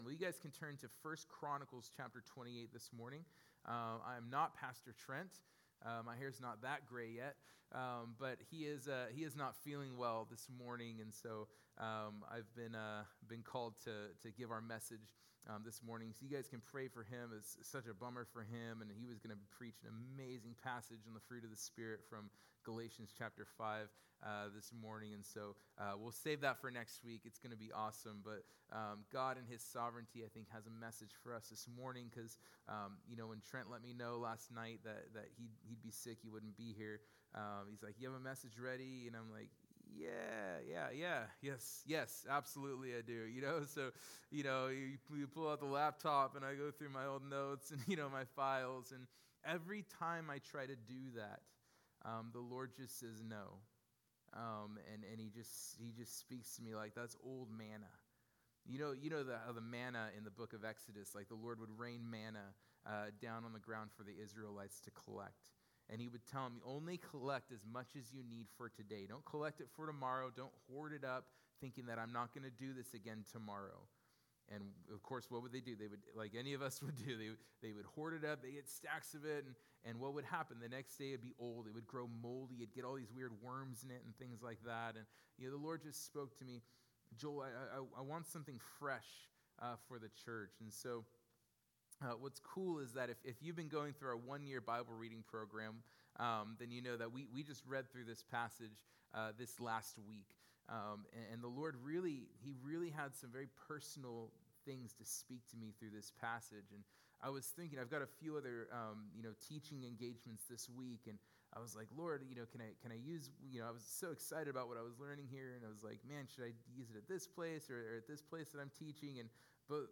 A+Heart+After+God's+Own+Heart+2nd+Service.mp3